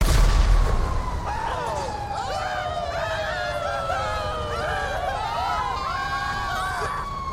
gta v npc from Game SFX
Genre: sound effects